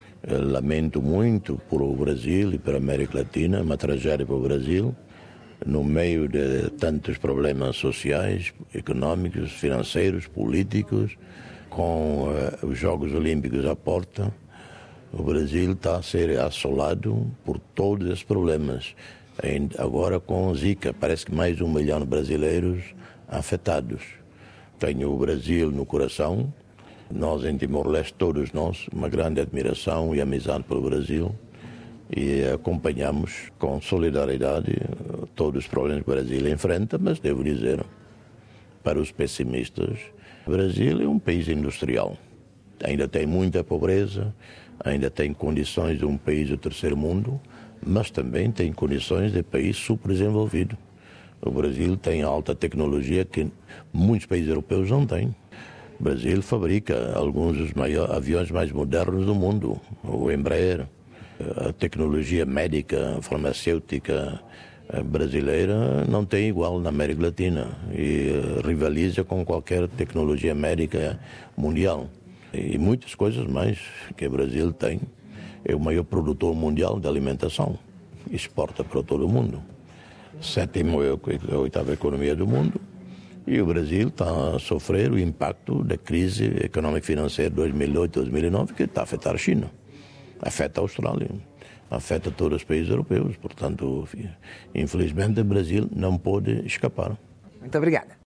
Dr José Ramos-Horta em entrevista ao Programa Português da Rádio SBS em Sydney Source: SBS Portuguese